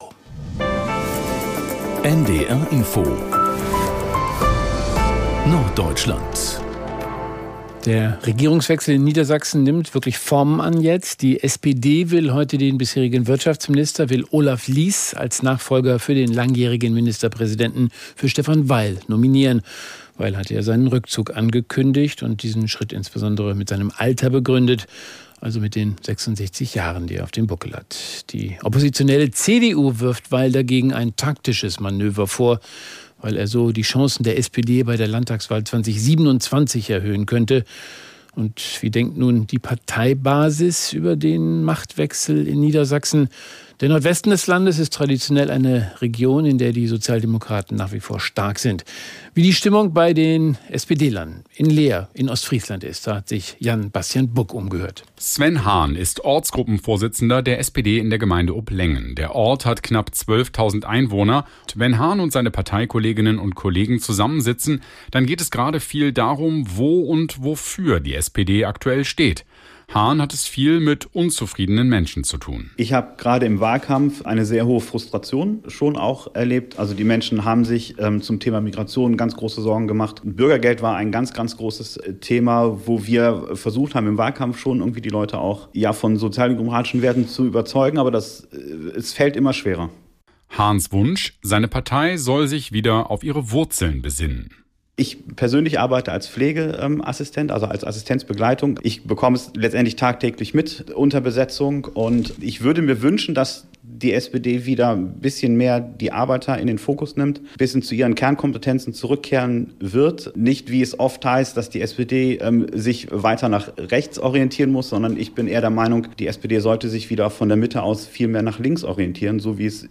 … continue reading 540 epizódok # Nachrichten # NDR Info # Tägliche Nachrichten